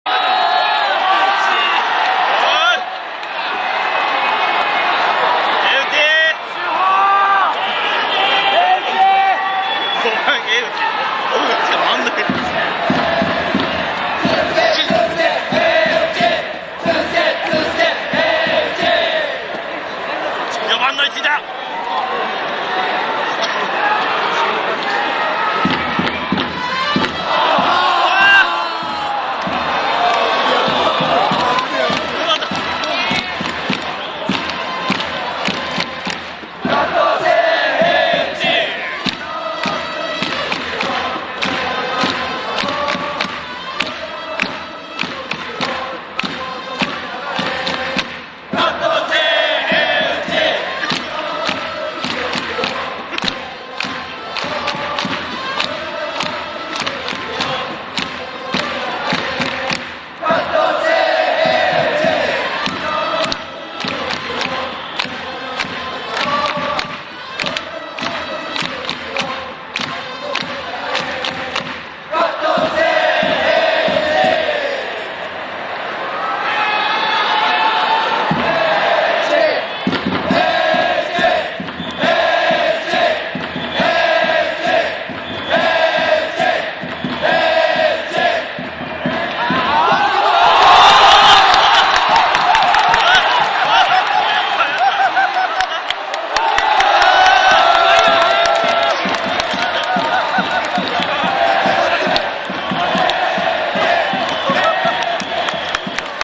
sound of stadium
このコーナーは管理人が隠れながら（？）球場で録音した音を公開していくコーナーです。